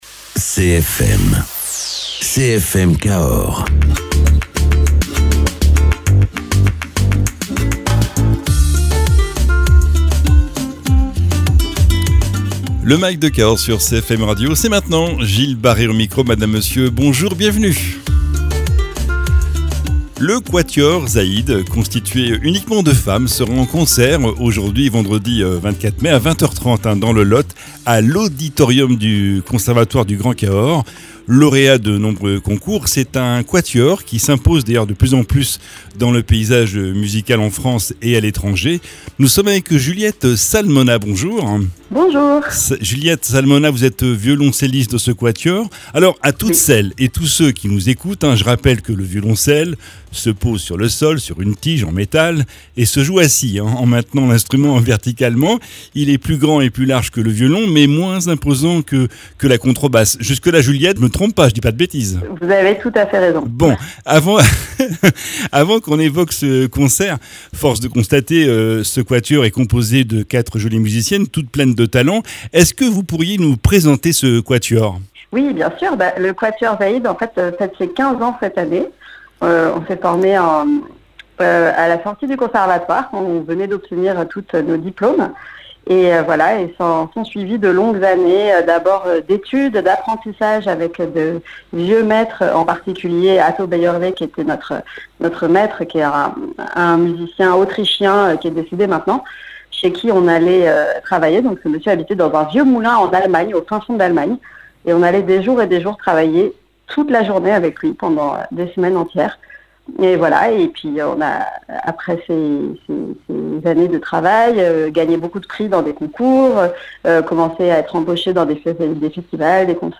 violoncelliste